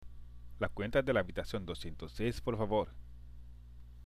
（ラクエンタ　デラアビタシオン　ドシエントッセイス　ポルファボール）